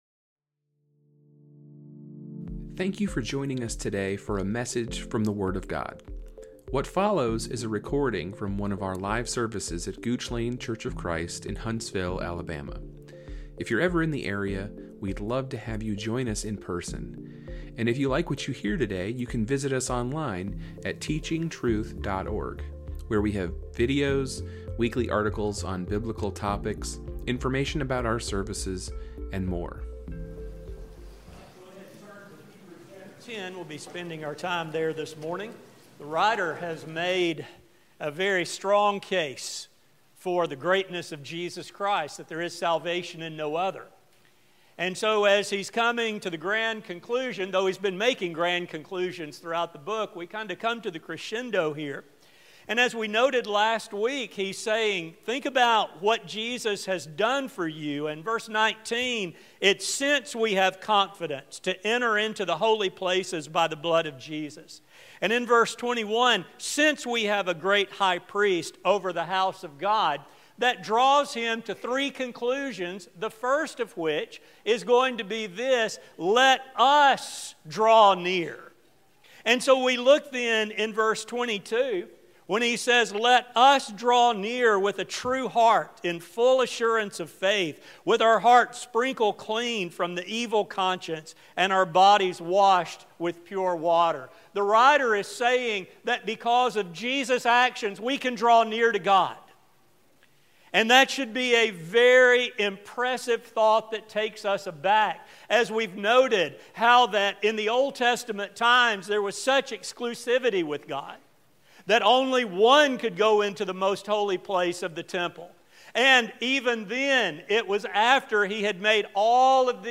This sermon will examine the encouragement given to early Christians who read the letter of Hebrews. It will focus on how the writer encouraged their faithfulness and how we, in turn, can also be encouraged to stay the course.